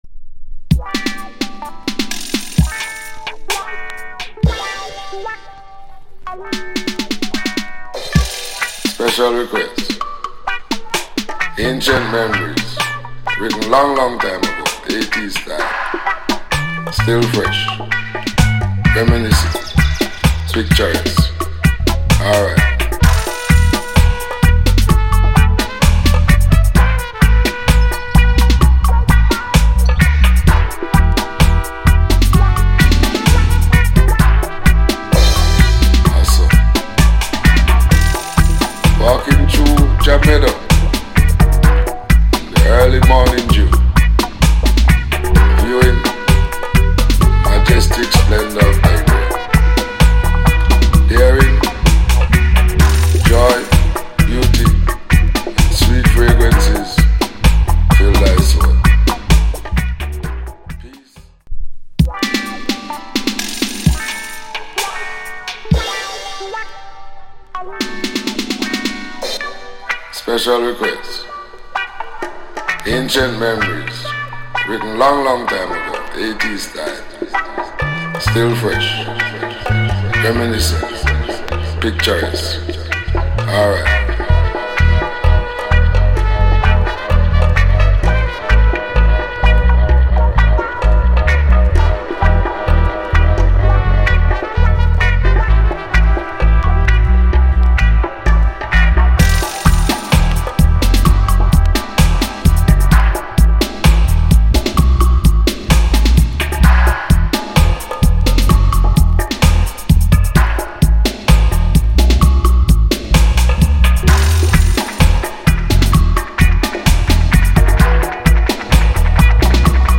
タフなベース・ラインのダブ・ポエット。